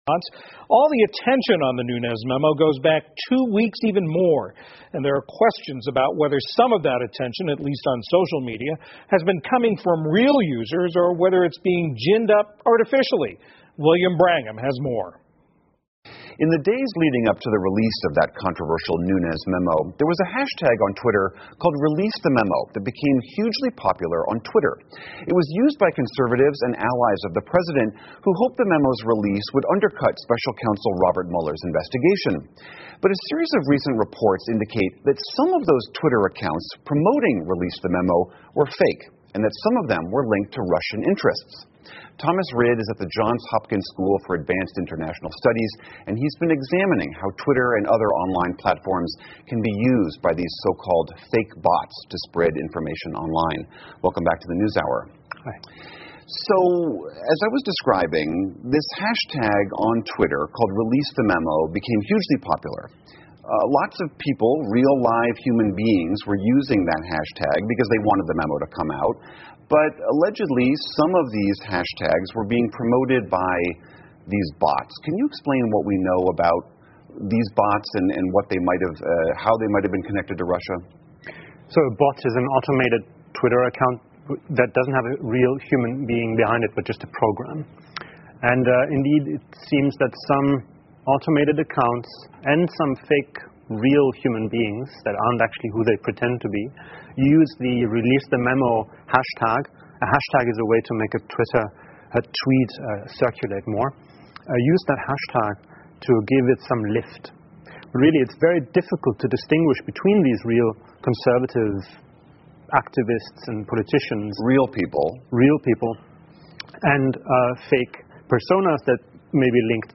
PBS高端访谈:推特机器人如何再用冷战方法散布政治分歧 听力文件下载—在线英语听力室